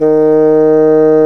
Index of /90_sSampleCDs/Roland L-CDX-03 Disk 1/WND_Bassoons/WND_Bassoon 2
WND BASSOO09.wav